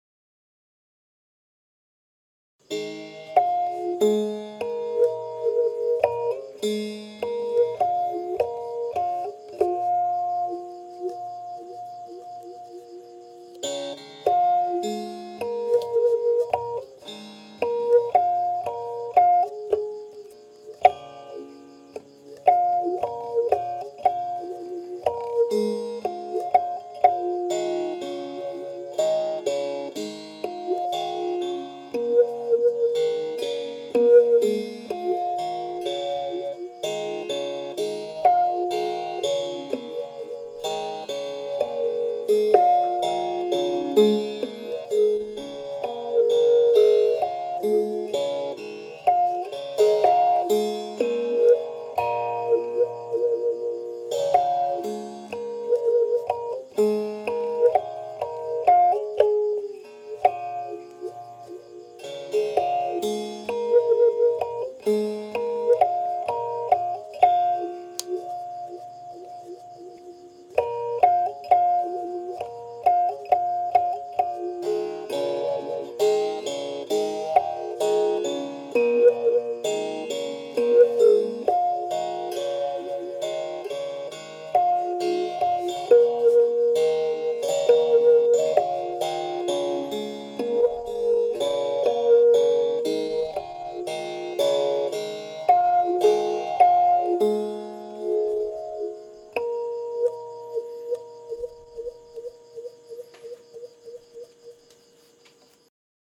พิณเปี๊ยะ
คำอธิบาย : กล่าวกันว่า "พิณเปี๊ยะ" หรือ "เปี๊ยะ" เป็นเครื่องดีดตระกูลพิณที่ไพเราะ เสียงเบา และ เล่นยากที่สุดอย่างหนึ่งในบรรดาเครื่องดนตรีทั้งหมดทั้งมวล “โฮงซึงหลวง เมืองลอง แพร่” หนึ่งในผู้ร่วมโครงการฯ ได้รวมกลุ่มนักดนตรีอีสาน จะเป็นผู้บรรยายหลักและแสดงการละเล่นในการจัดทำสื่อวีดิทัศน์ในครั้งนี้
คำสำคัญ : เมืองลอง, พิณเปี๊ยะ, ล้านนา, กล่อมนางนอน, พิณ, เครื่องดนตรี, พื้นบ้าน, แพร่, โฮงซึงหลวง